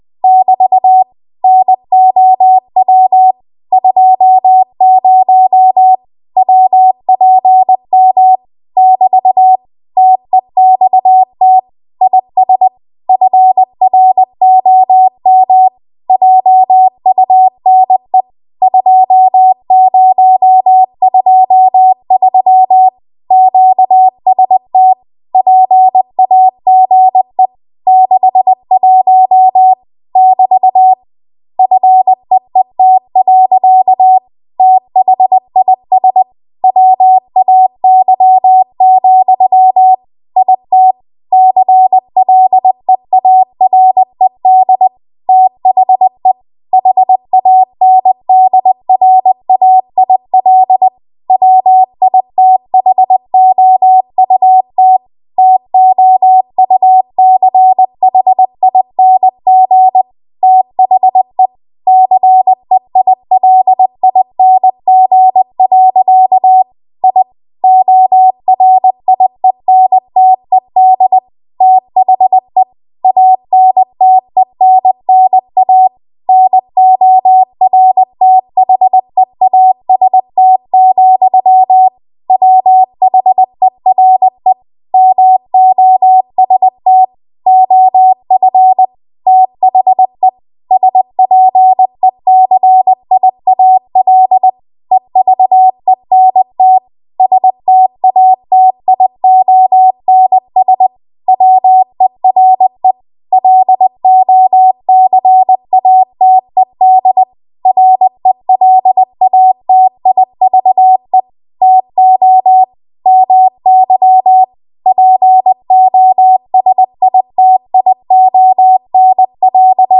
20 WPM Code Practice Archive Files
Listed here are archived 20 WPM W1AW code practice transmissions for the dates and speeds indicated.
You will hear these characters as regular Morse code prosigns or abbreviations.